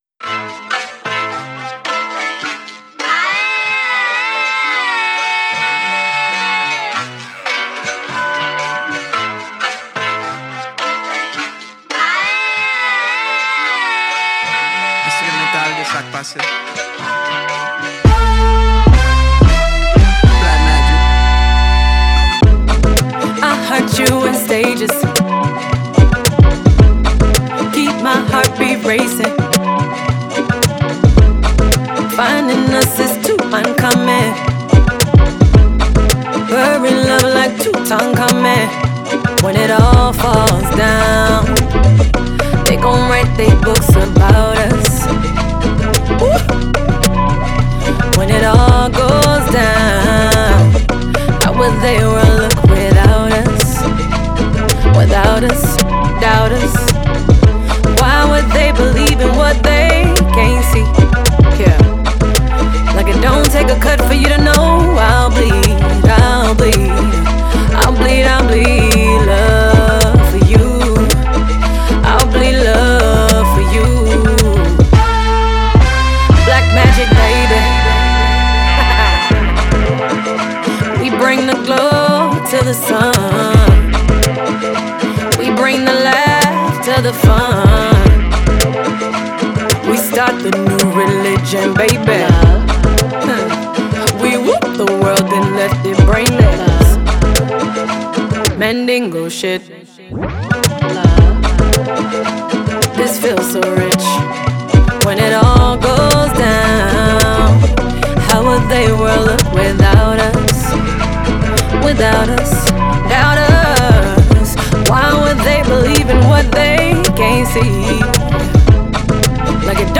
зажигательная поп- и R&B-композиция